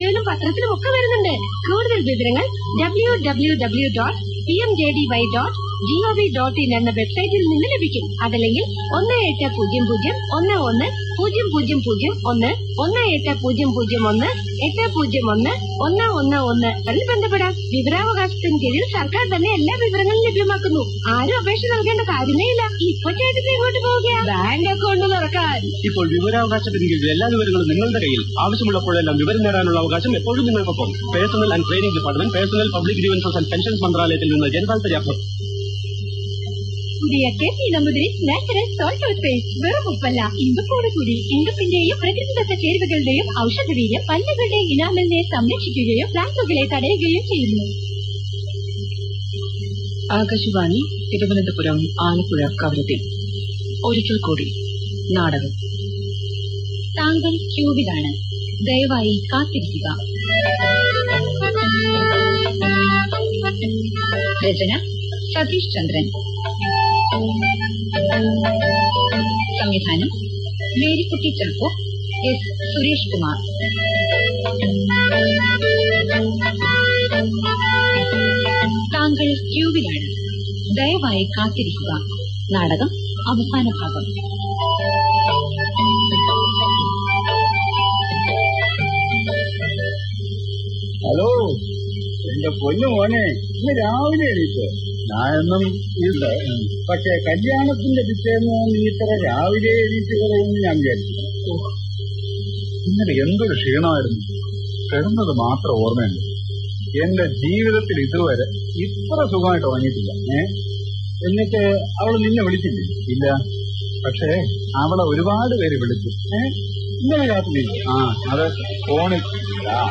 റേഡിയോ (വളിപ്പ്) നാടകം
radio-naadakam.mp3